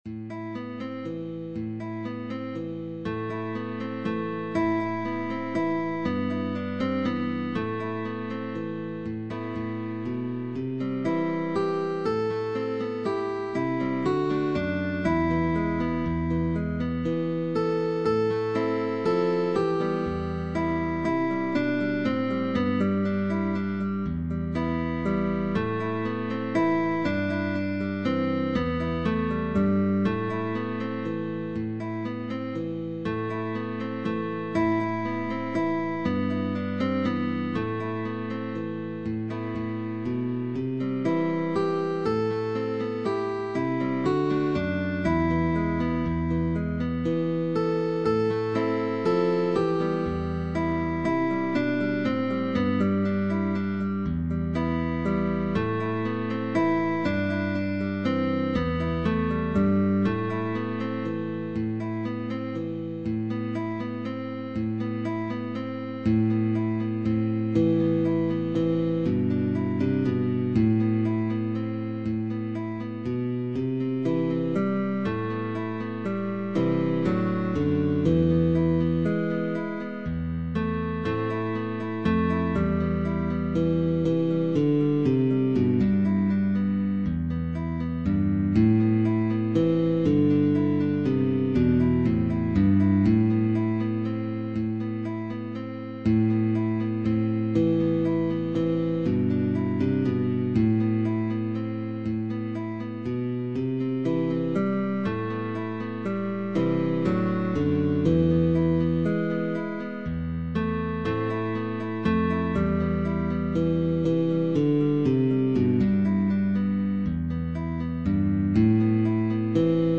Categories: Didactic Works